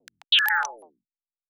pgs/Assets/Audio/Sci-Fi Sounds/Interface/Data 19.wav at master